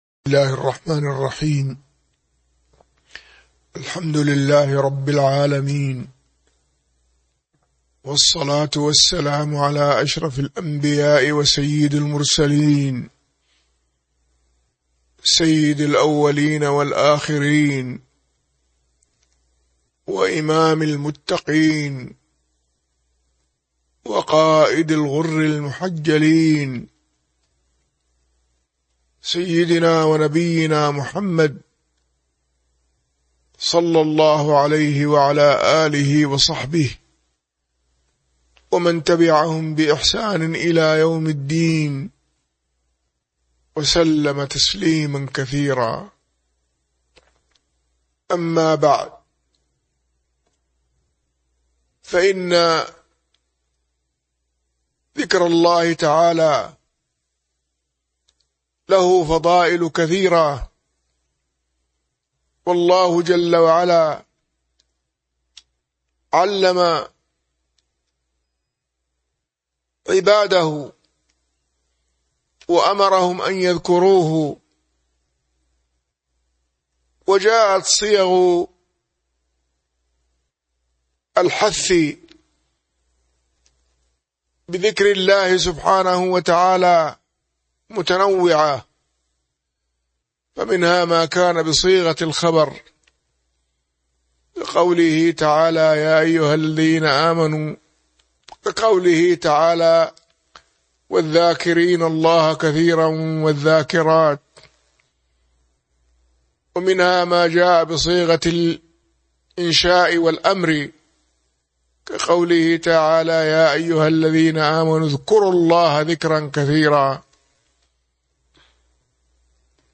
تاريخ النشر ١٥ ذو القعدة ١٤٤٢ هـ المكان: المسجد النبوي الشيخ